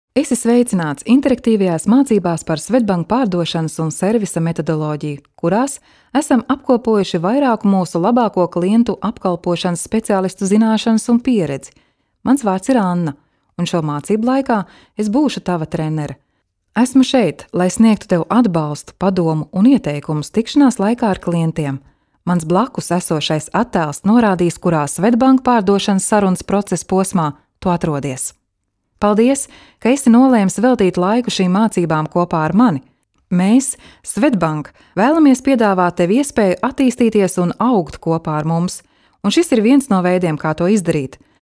Kadın Ses